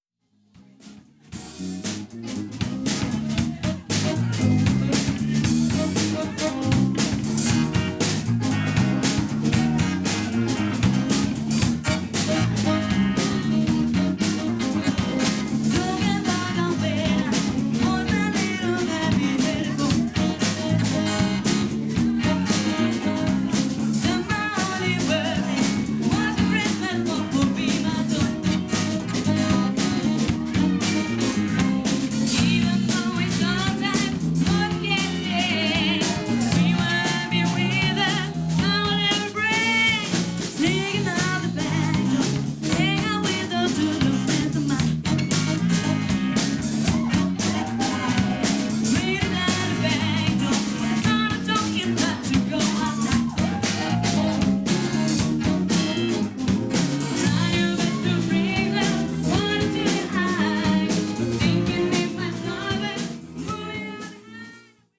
• Coverband
• Soul/Funk/Groove